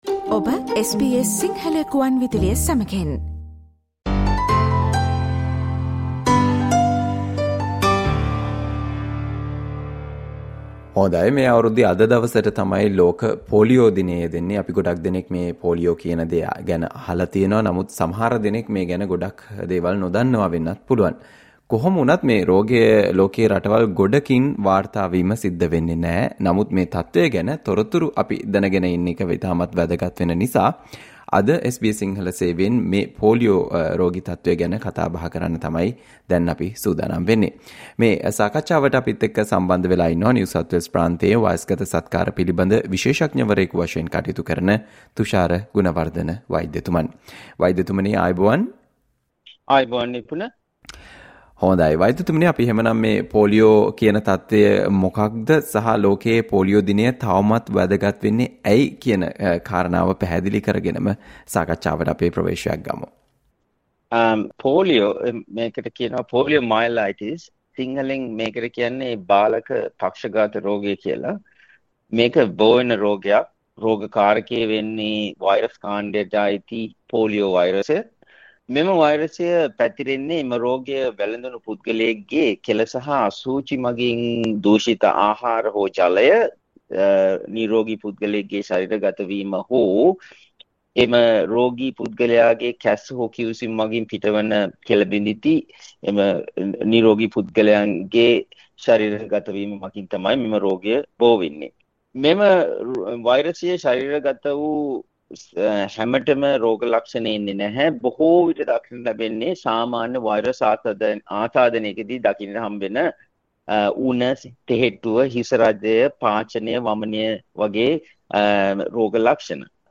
2025 ඔක්තෝබර් 24 වැනිදාට යෙදෙන “ලෝක පෝලියෝ දිනයට” සමගාමීව පෝලියෝ සහ එම තත්වය ගෝලීය වශයෙන් තුරන් කිරීමේ උත්සාහයන් පිළිබඳව මහජනතාව දැනුවත් කිරීම ඉලක්ක කරගනිමින් SBS සිංහල සේවය සිදු කල සාකච්ඡාවට සවන් දෙන්න